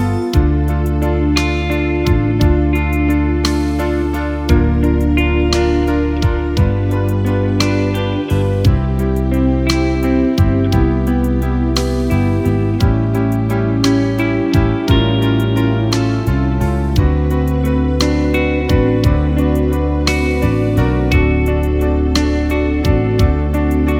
No Backing Vocals Crooners 3:59 Buy £1.50